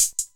Closed Hats
TrapDuo.wav